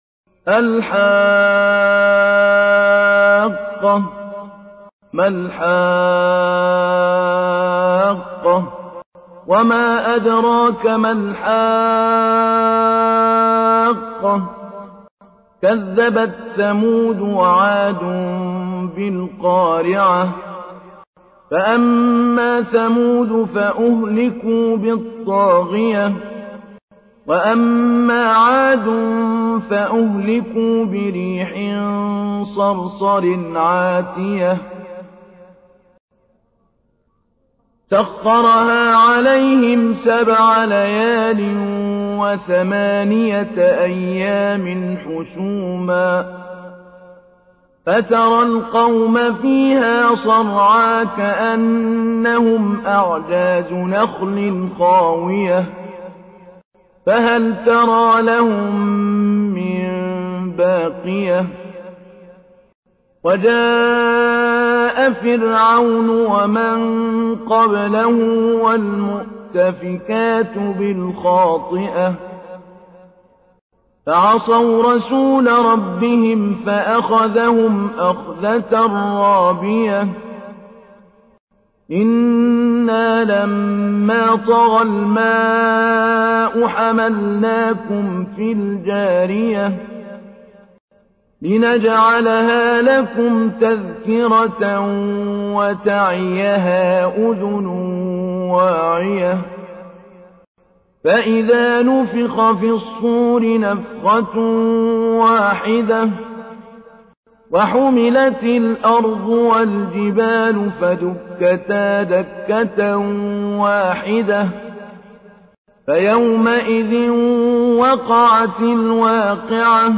تحميل : 69. سورة الحاقة / القارئ محمود علي البنا / القرآن الكريم / موقع يا حسين